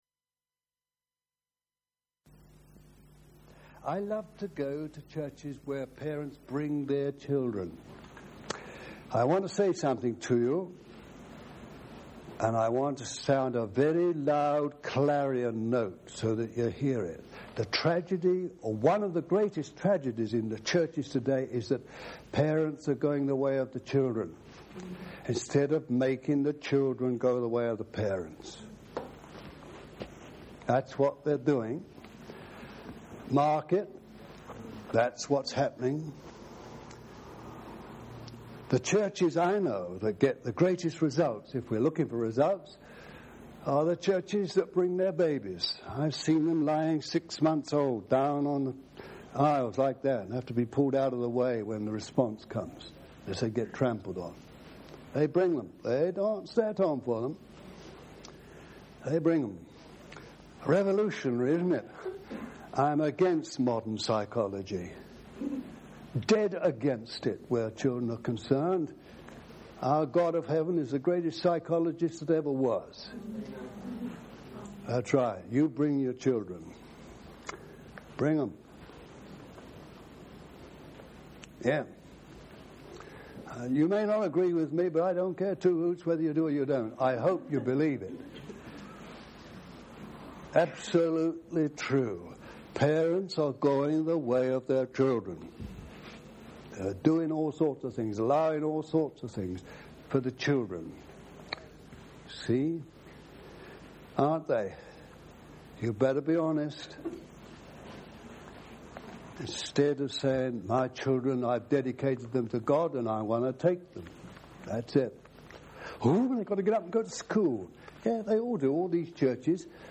Message
No 3 of 6 messages on the Fire of God, given over a period of 4 years at Rora House CF, Devon; Devonshire Rd CF Liverpool; the Longcroft CF, Wirral